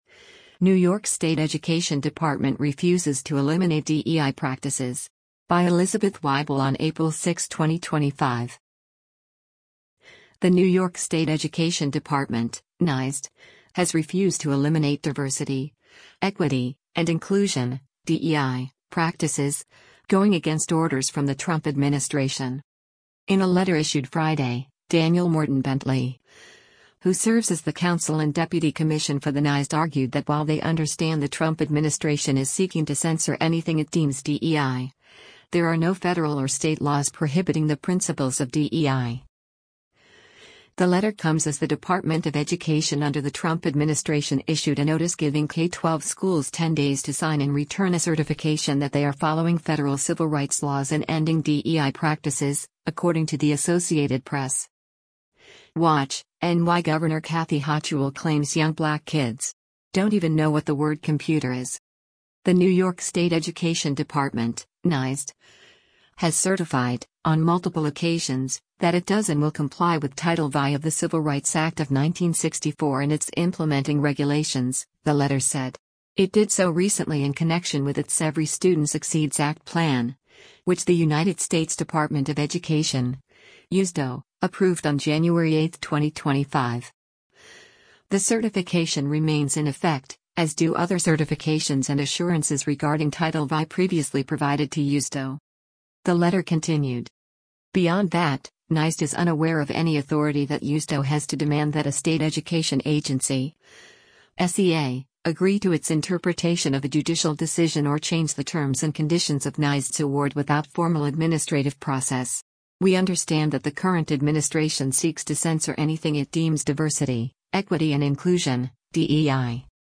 New York Gov. Kathy Hochul speaks during a press conference at her Manhattan office on Feb